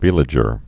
(vēlə-jər, vĕlə-)